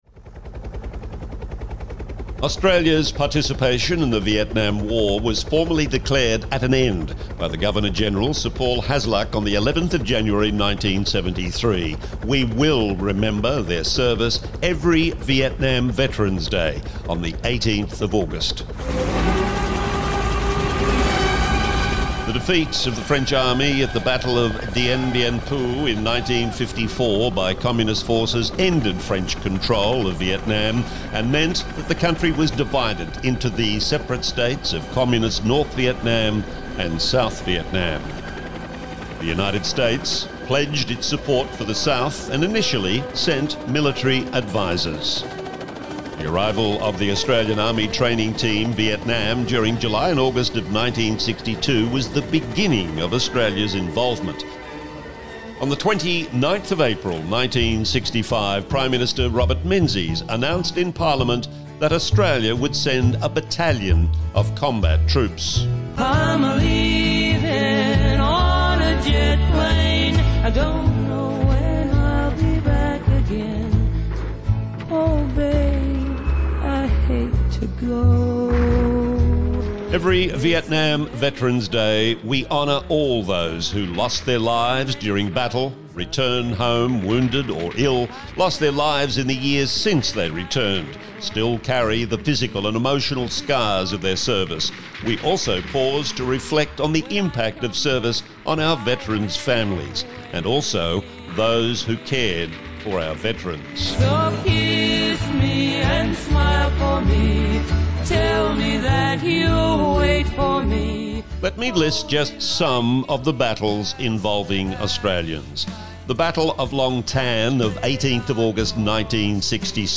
VIET-VETS-AUGUST-18-DOCUMENTARY.mp3